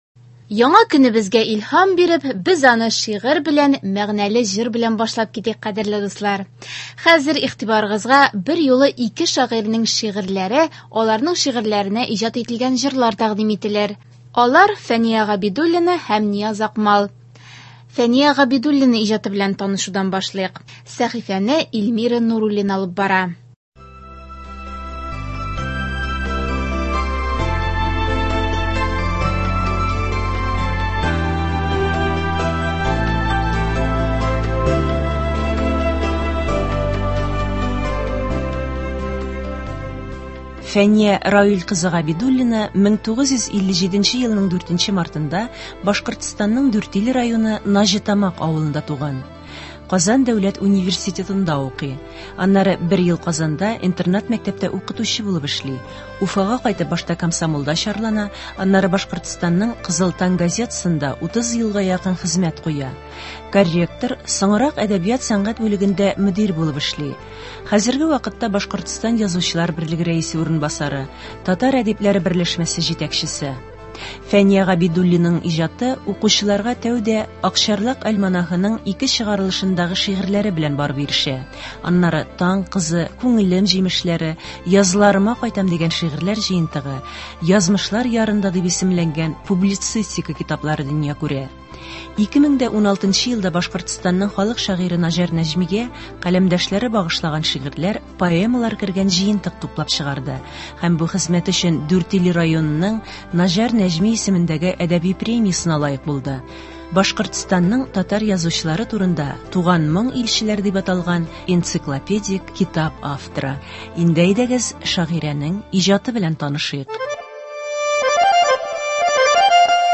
Әдәби-музыкаль композиция. 15 февраль.